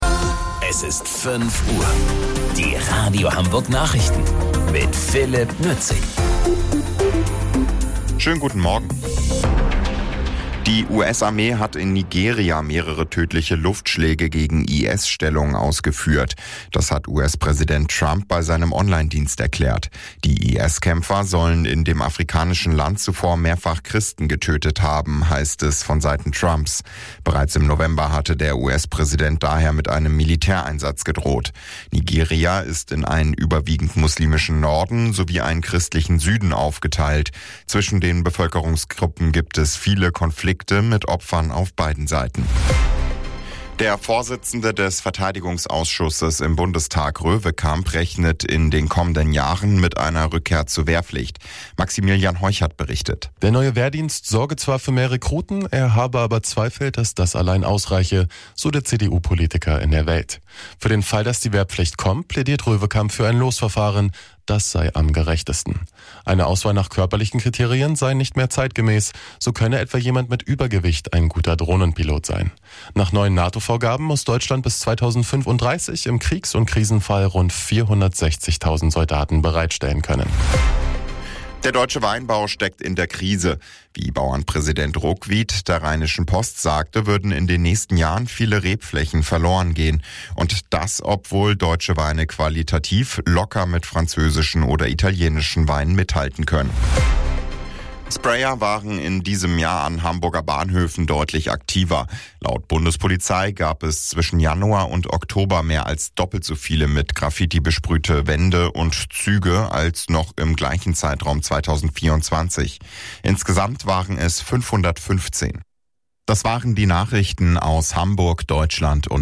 Radio Hamburg Nachrichten vom 26.12.2025 um 05 Uhr